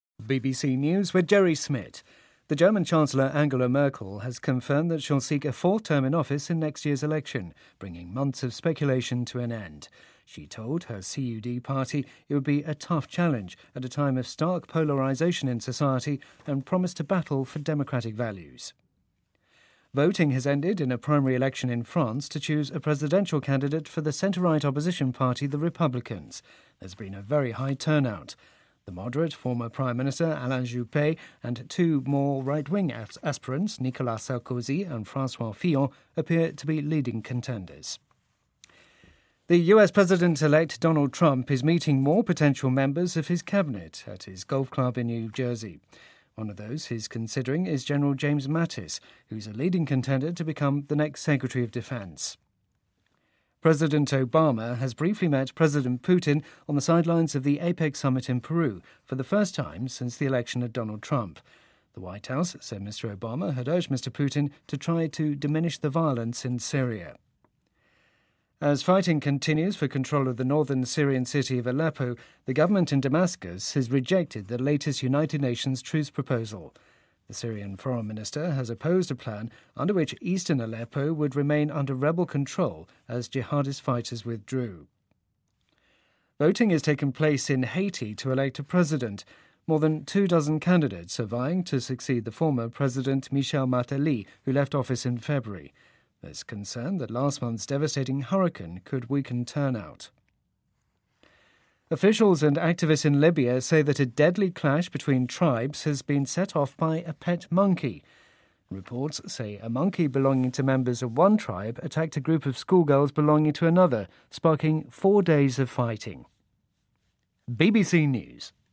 BBC news,德国总理默克尔寻求第四次连任